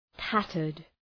Προφορά
{‘tætərd}